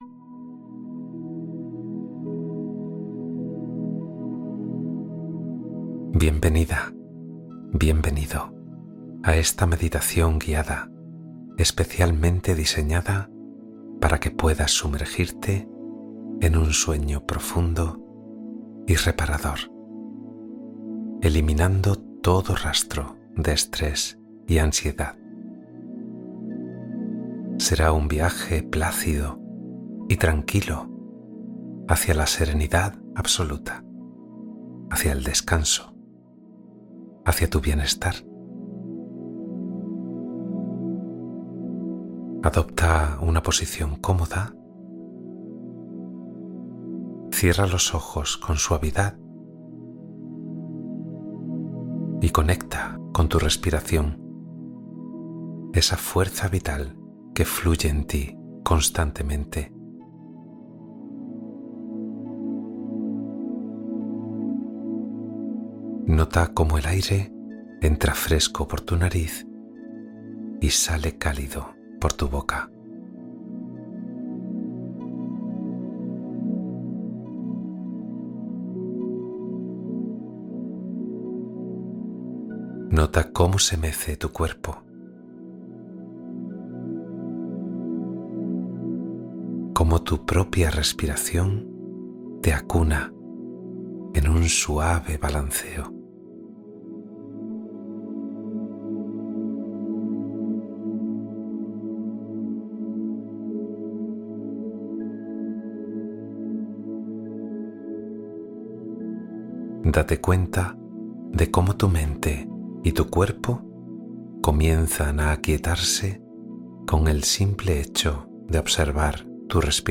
Meditación relajante para dormir sin estrés ni ansiedad nocturna